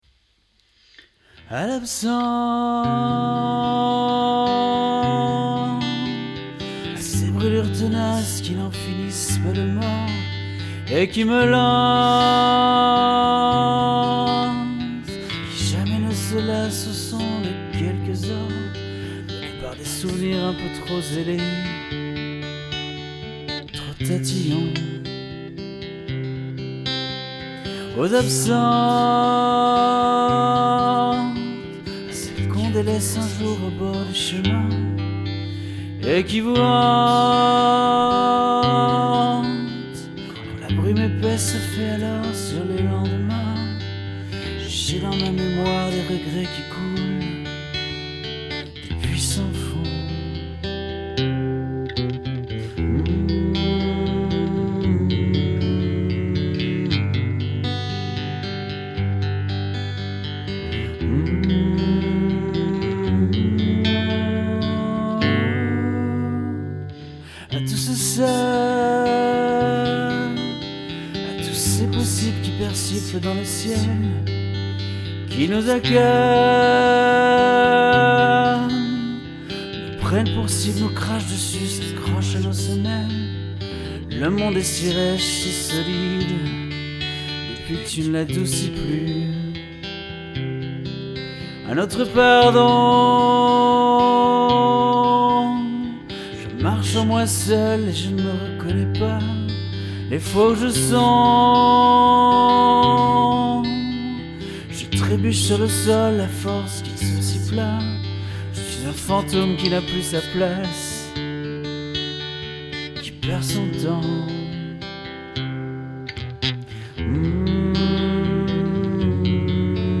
Version “normale”, enregistrée le 24 février 2015.
• Voix (le serviteur souffrant)
• 2ème voix (le fantôme)
• Guitare